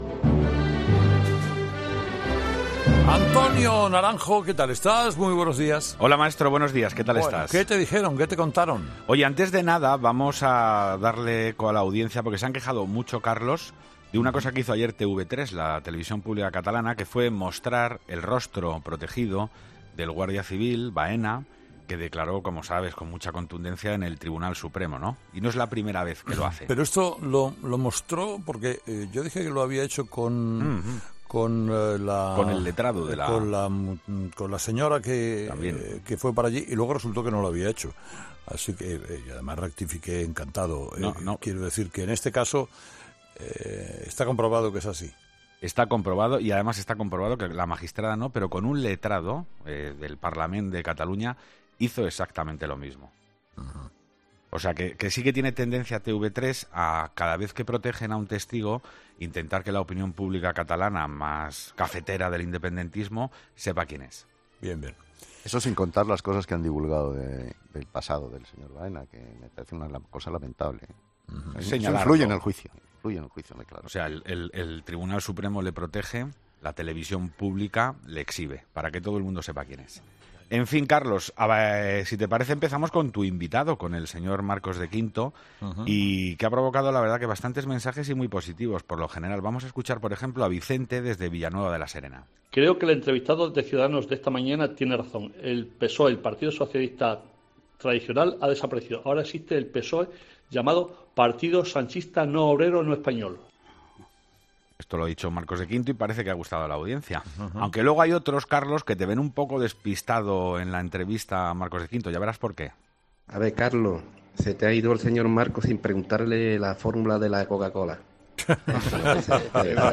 La Tertulia de los Oyentes es la sección que Herrera ofrece a sus seguidores para que opinen sobre los temas de actualidad.